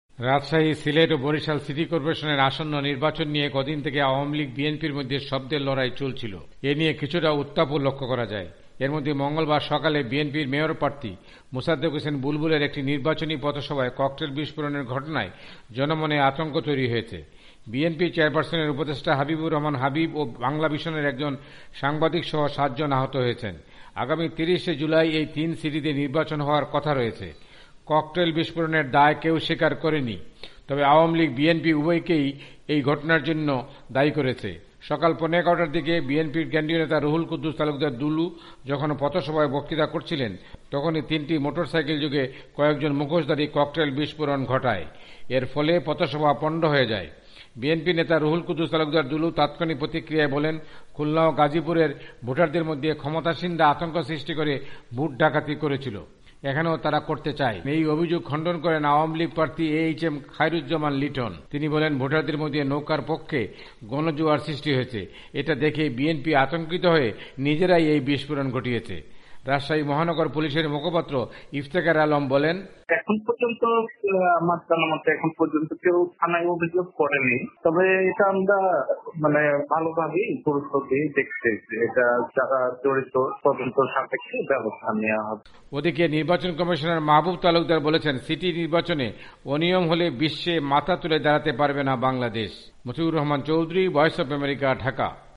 রিপোর্ট রাশাহী